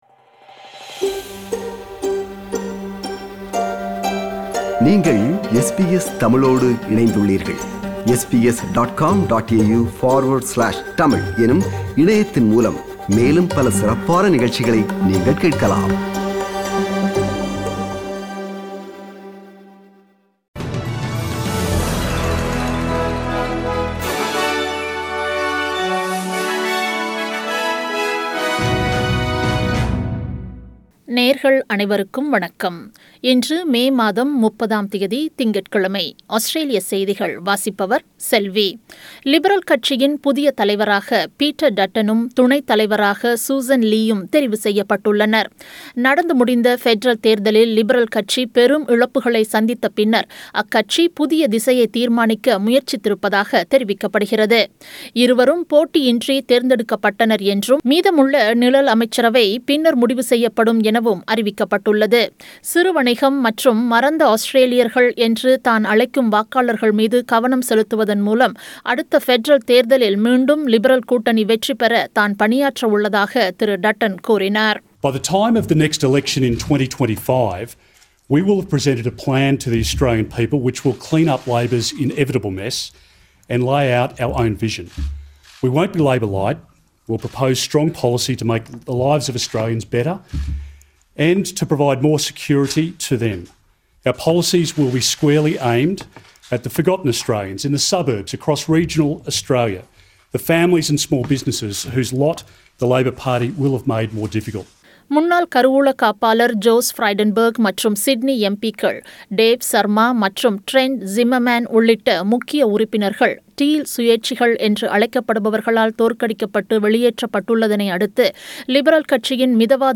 Australian news bulletin for Monday 30 May 2022.